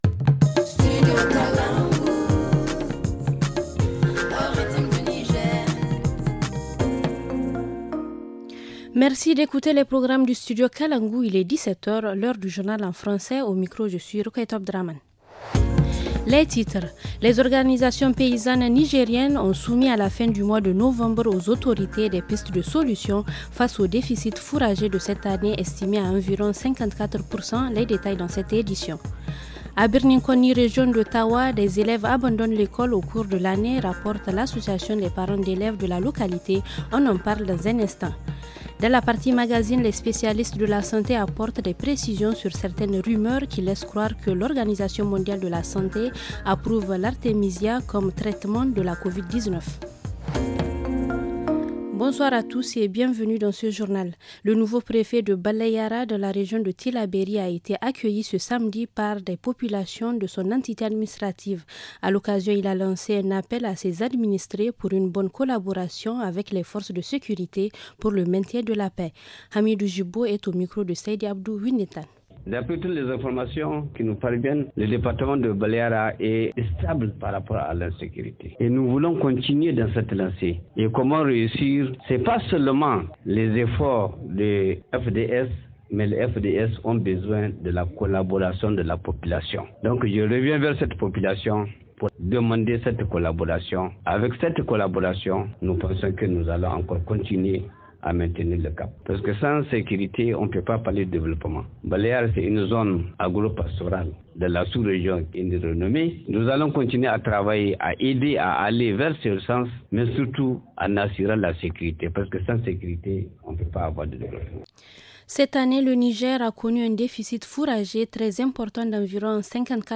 Le journal du 8 décembre 2021 - Studio Kalangou - Au rythme du Niger